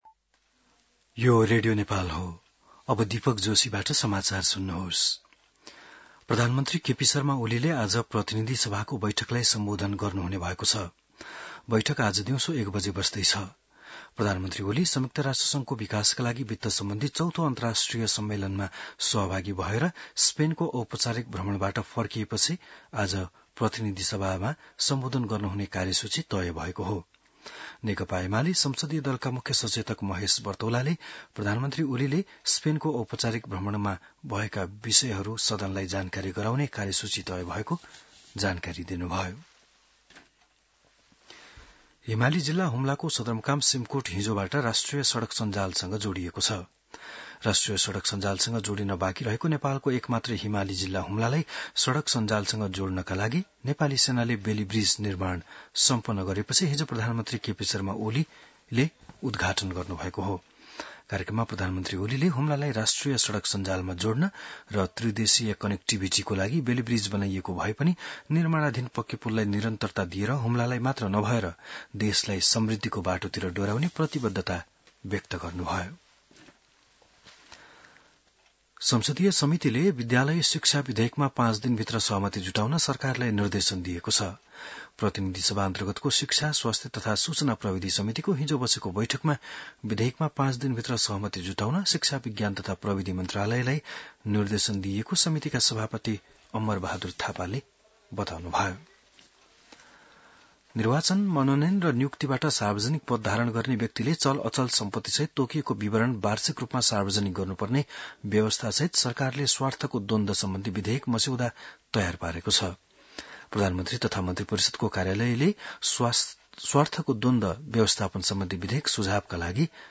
बिहान १० बजेको नेपाली समाचार : २३ असार , २०८२